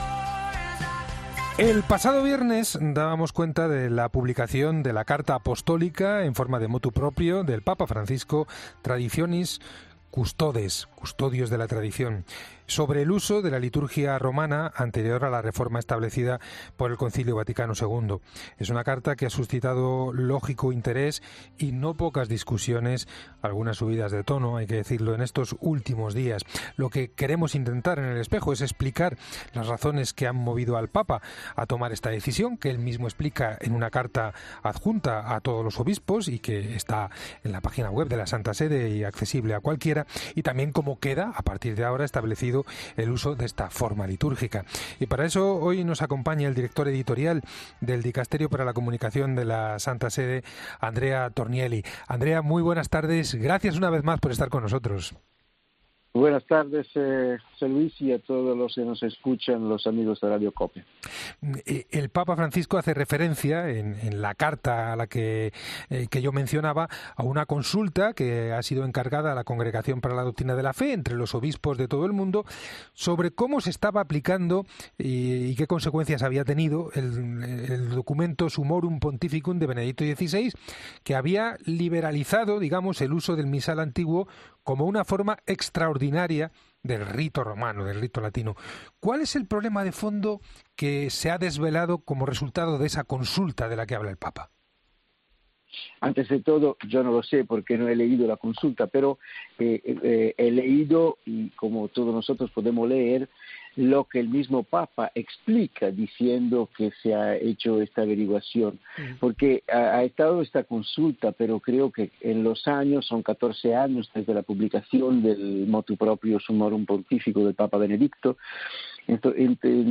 Para ayudar a dar respuesta a todas estas preguntas ha pasado por los micrófonos de la Cadena Cope